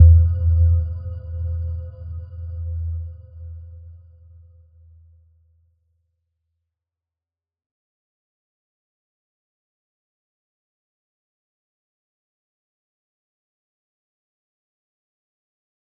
Little-Pluck-E2-f.wav